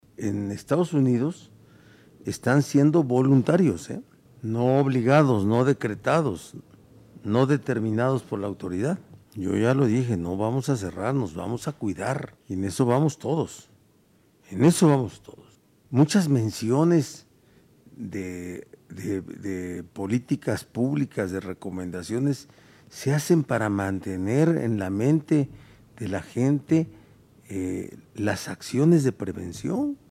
En conferencia de prensa, el mandatario estatal informó que a pesar de la posible presencia de la variante sudafricana en territorio poblano, el índice de positividad y hospitalizaciones Covid siguen a la baja.